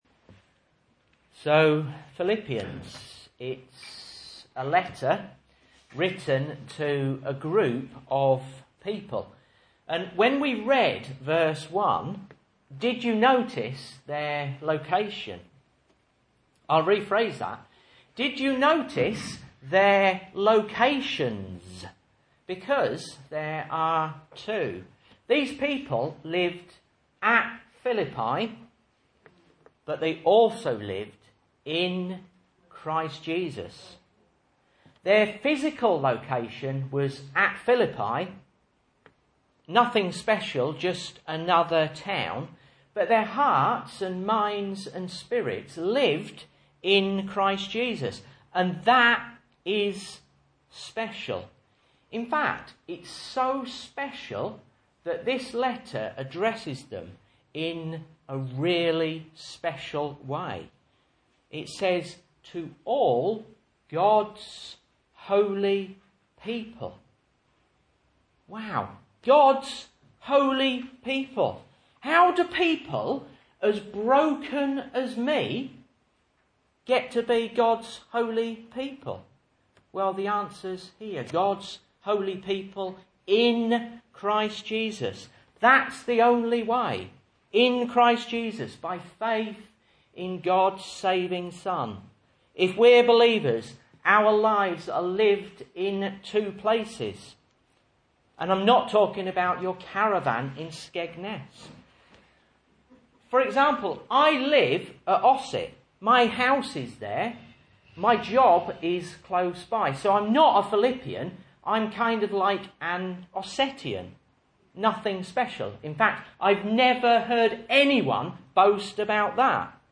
Message Scripture: Philippians 1:1-2 | Listen